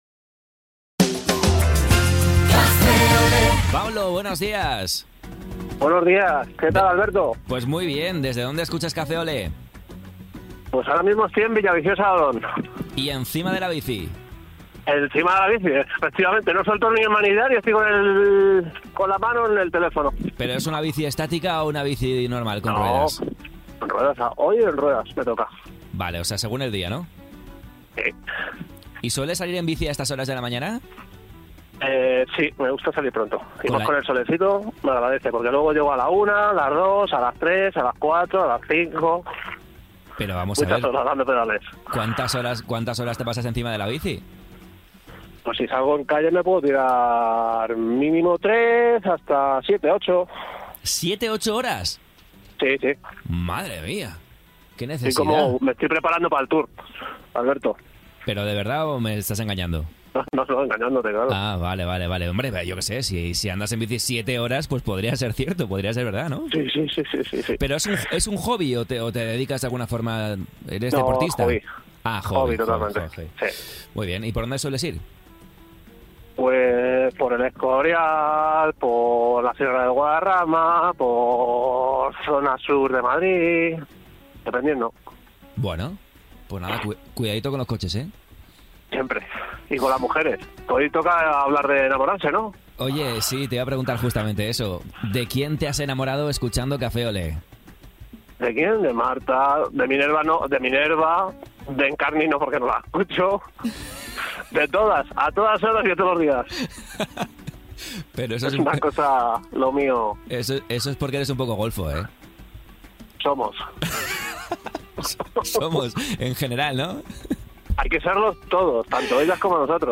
Café Olé: Un pipiolo confiesa que está enamorado de la de informativos